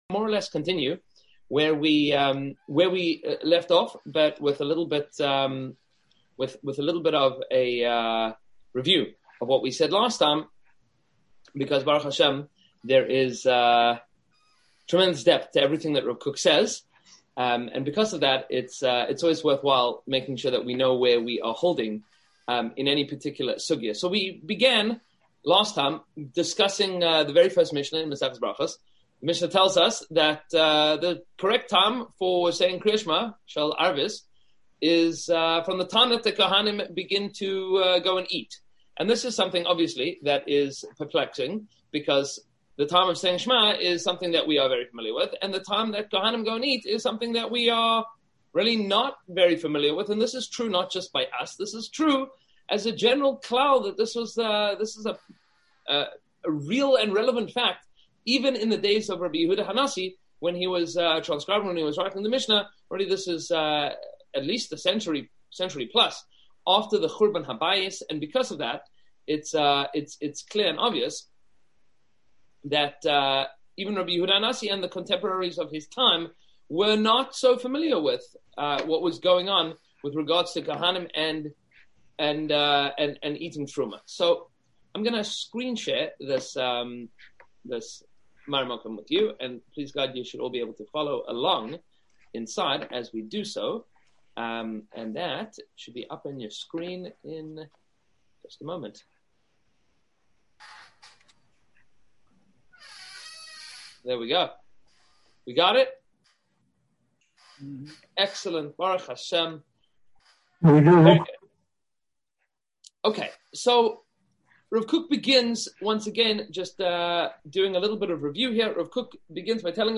Venue :Boca Raton Synagogue